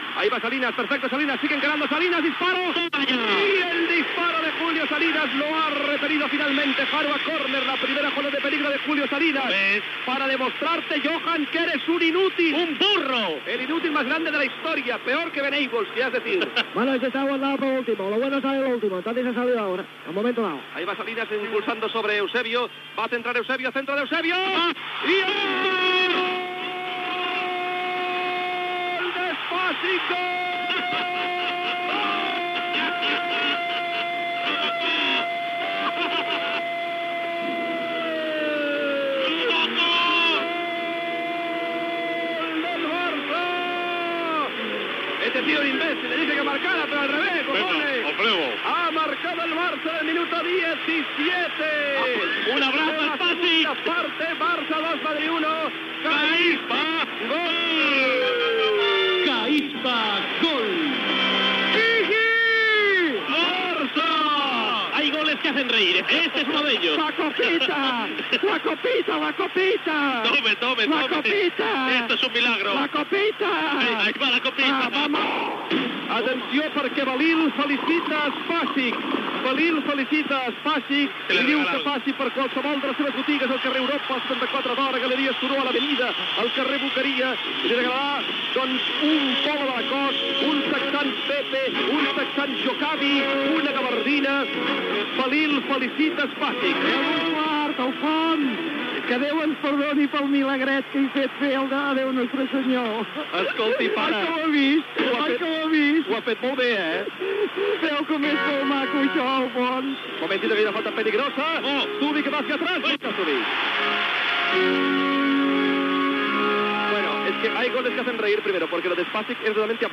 Transmissió del partit de futbol masculí Barcelona - Real Madrid. Xut de Salinas, córner i autogol del jugador del Real Madrid Spasic.
Esportiu